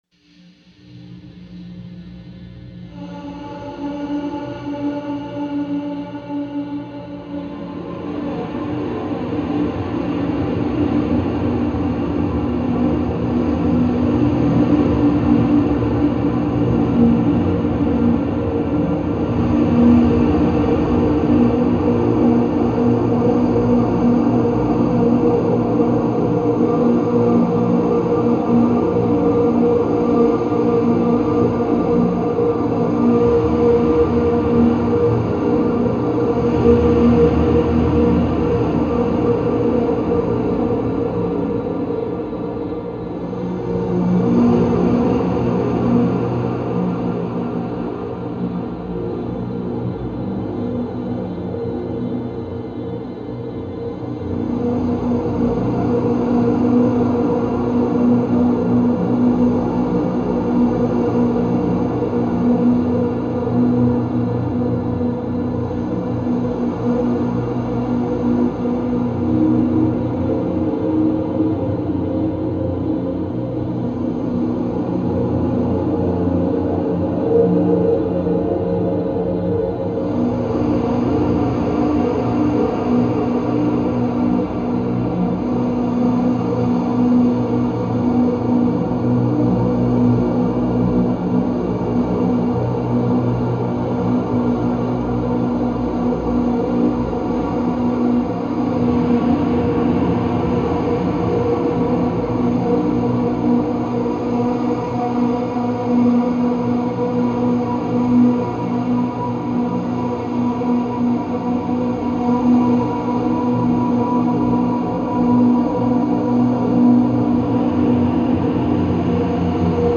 Enjoy two pieces, with an interview tacked in the middle